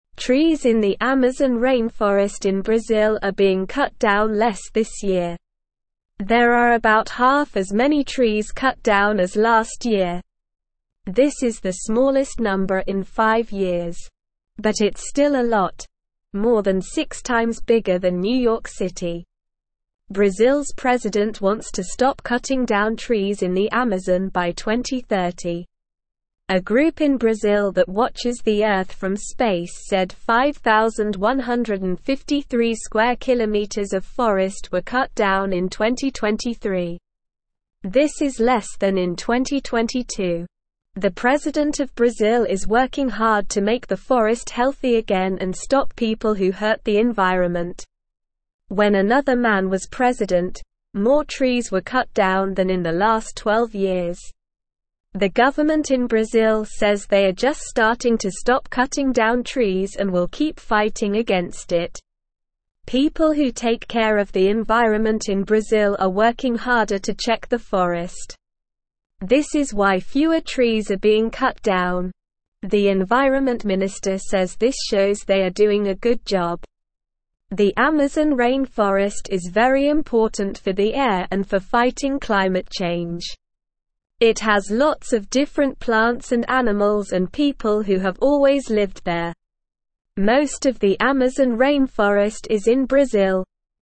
Slow
English-Newsroom-Lower-Intermediate-SLOW-Reading-Brazils-Forest-Fewer-Trees-Cut-Down-But-Still-Too-Many.mp3